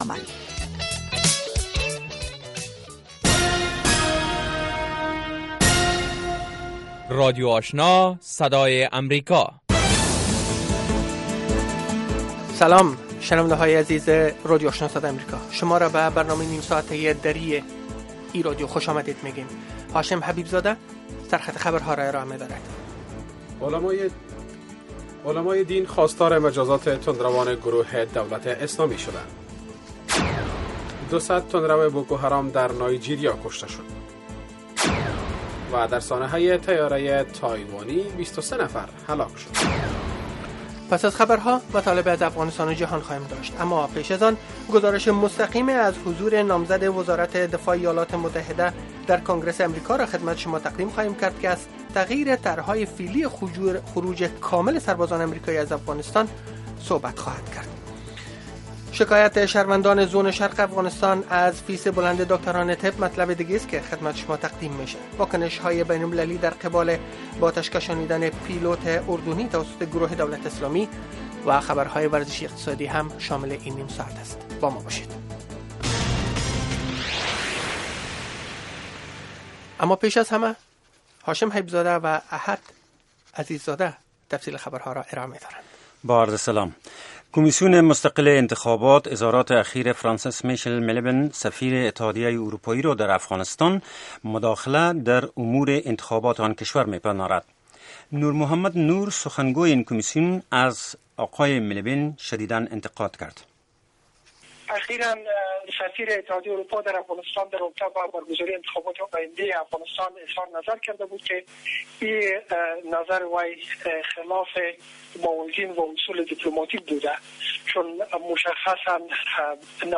در نخستین برنامه خبری شب خبرهای تازه و گزارش های دقیق از سرتاسر افغانستان، منطقه و جهان فقط در سی دقیقه.